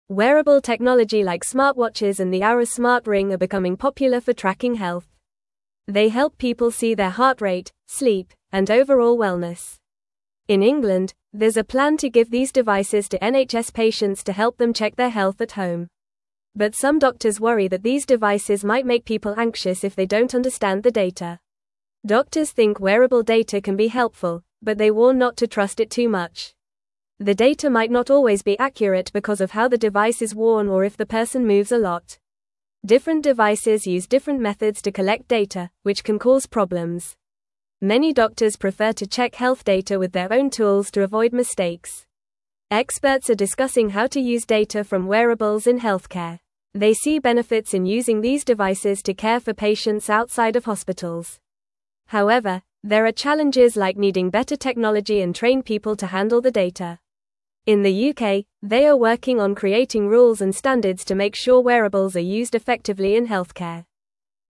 Fast
English-Newsroom-Lower-Intermediate-FAST-Reading-Smart-Devices-Help-Check-Health-at-Home.mp3